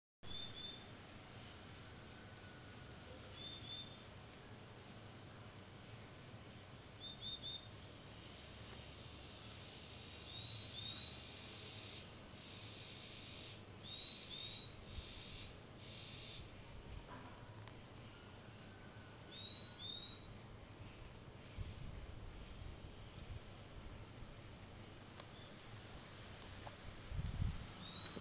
Purple-throated Euphonia (Euphonia chlorotica)
Sex: Female
Location or protected area: Posada Las Huellas
Condition: Wild
Certainty: Photographed, Recorded vocal
Tangara-Comun.mp3